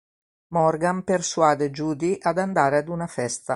/ˈfɛs.ta/